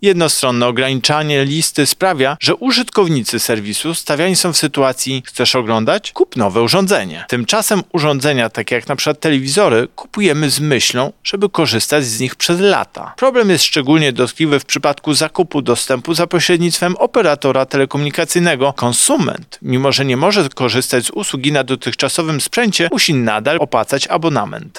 Chróstny UOKiK – mówi Tomasz Chróstny, prezes UOKiK